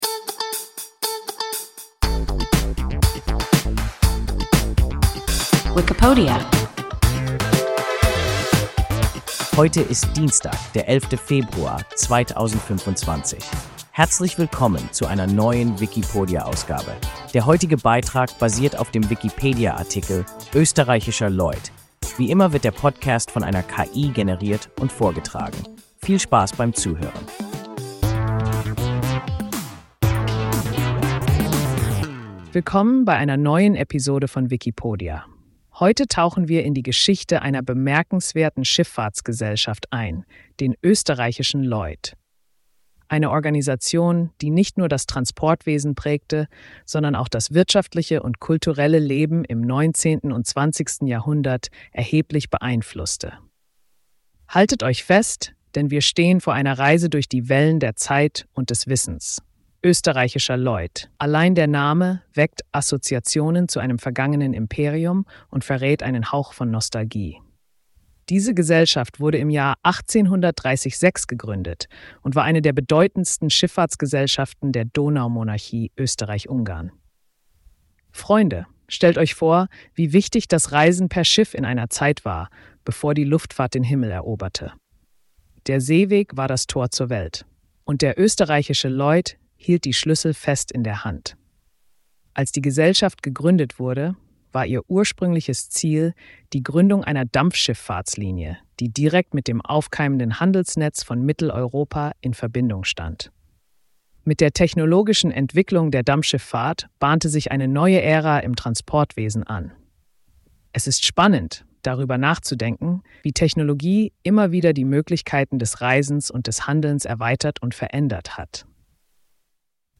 Österreichischer Lloyd – WIKIPODIA – ein KI Podcast